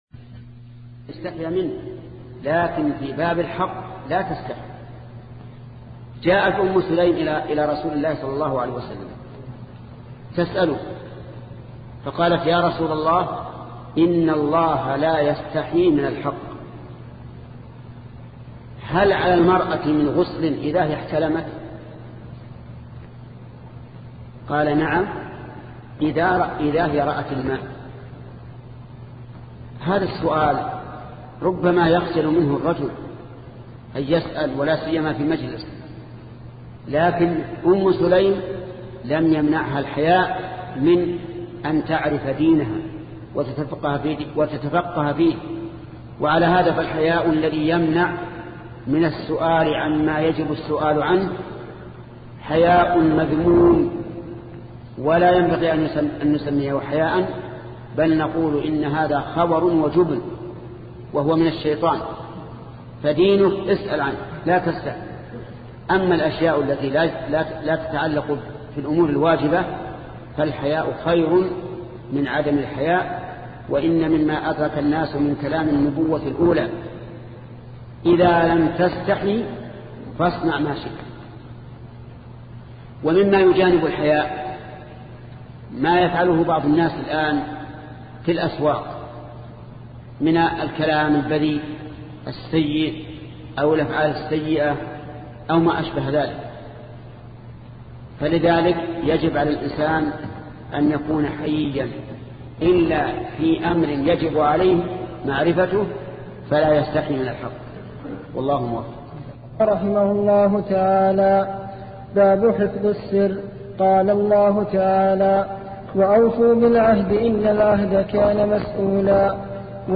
سلسلة مجموعة محاضرات شرح رياض الصالحين لشيخ محمد بن صالح العثيمين رحمة الله تعالى